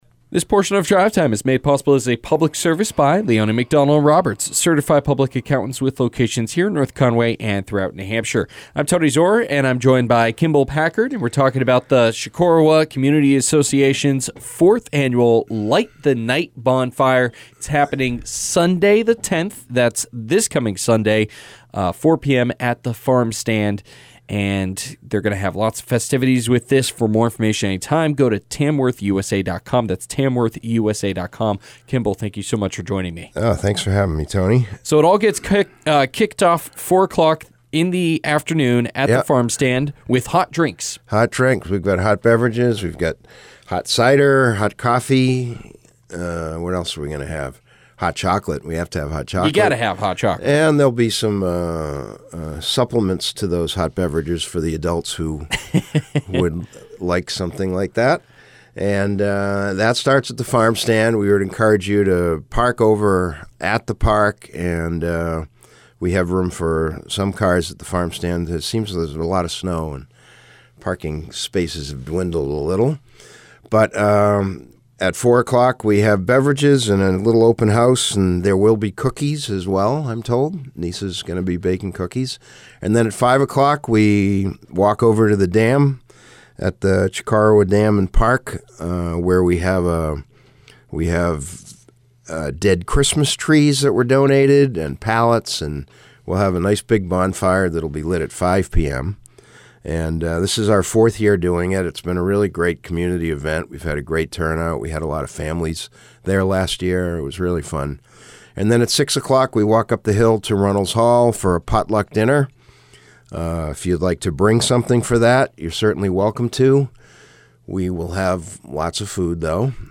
Drive Time Interviews are a specialty program on week days at 5pm where local not for profit organizations get a chance to talk about an upcoming event on air.